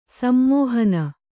♪ sammōhana